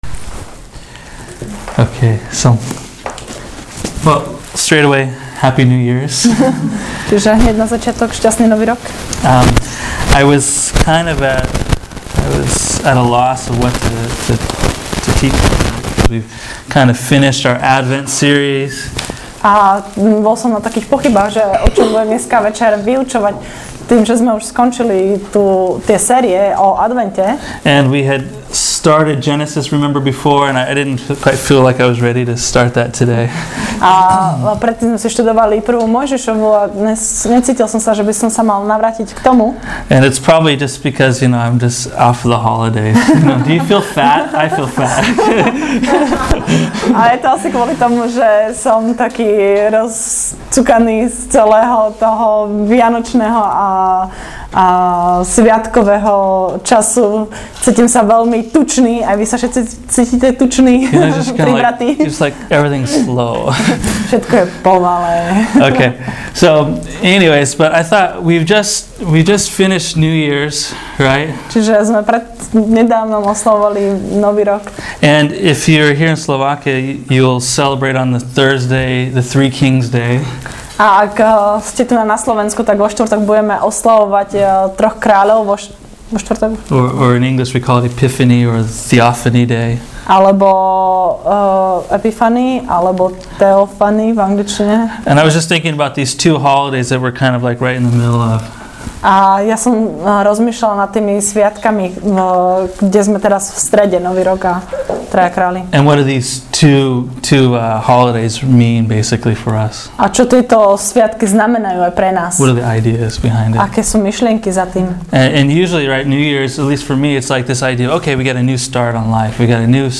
Teaching: “A Good Year” Event Pics: CityLight 2.1.2011 Share this: Share on Facebook (Opens in new window) Facebook Share on X (Opens in new window) X Like Loading...